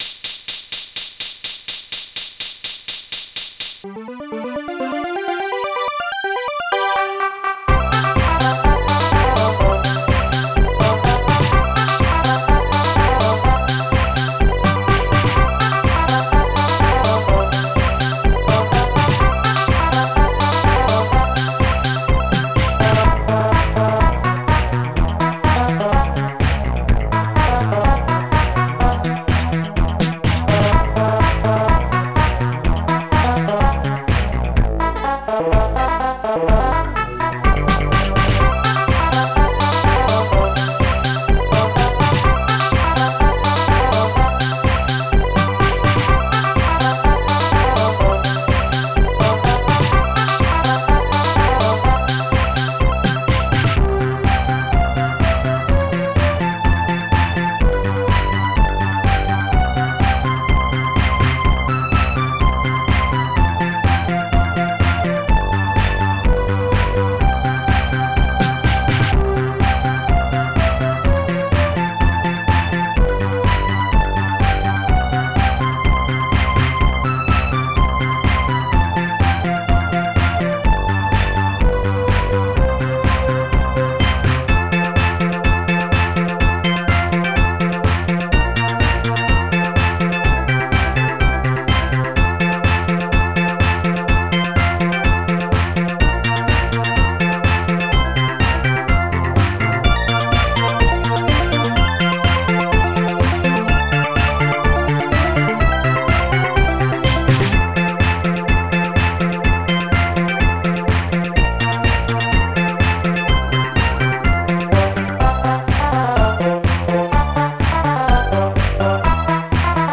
Protracker Module  |  1990-12-10  |  130KB  |  2 channels  |  44,100 sample rate  |  4 minutes, 13 seconds
st-02:dumpfbass
st-02:bassdrum5
st-02:snare8
st-02:sqbrass
st-01:pingbells
st-01:hihat2
st-01:strings3
st-01:epiano